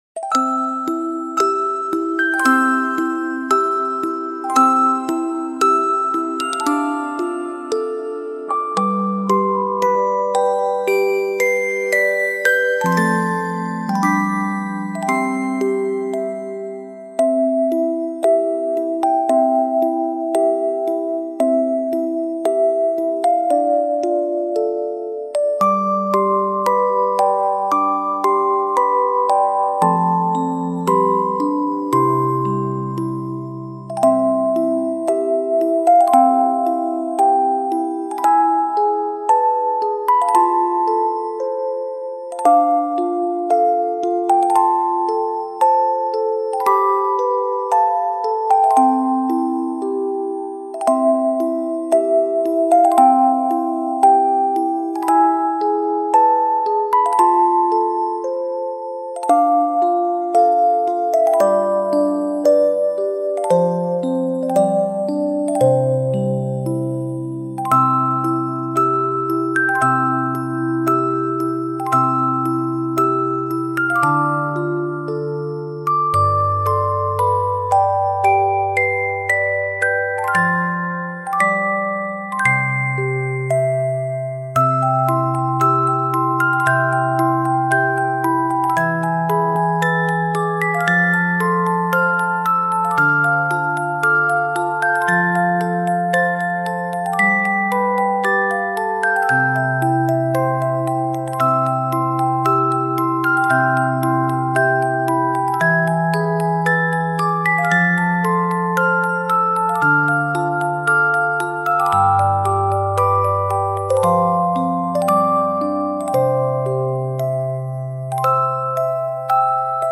Genres: Relaxing Music
Tempo: 112 bpm